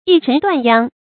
逸塵斷鞅 注音： ㄧˋ ㄔㄣˊ ㄉㄨㄢˋ ㄧㄤ 讀音讀法： 意思解釋： 指馬奔跑時揚起塵土，掙斷馬鞅。形容馬跑得很快。